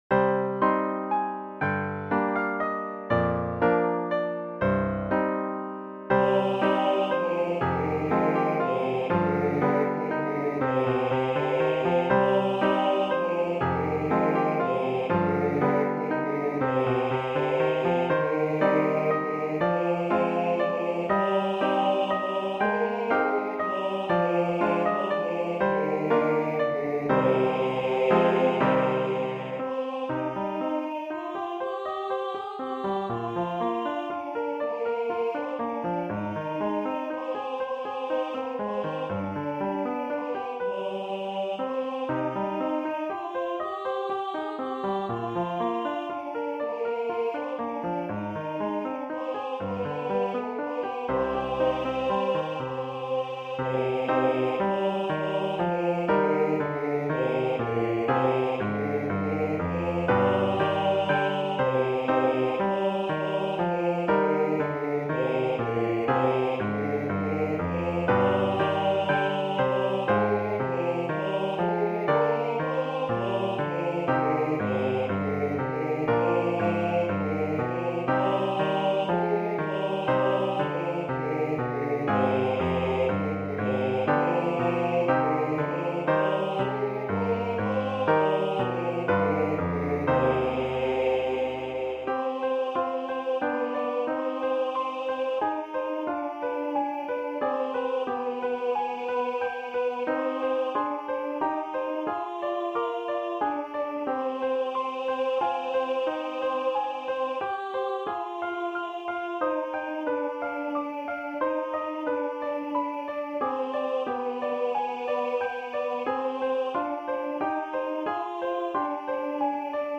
If you're looking for a medley of Christmas songs for your choir or other group that will work up fast, this is it! It's all unison and can be learned in one practice.
Voicing/Instrumentation: Choir Unison , Young Women Voices We also have other 64 arrangements of " The First Noel ".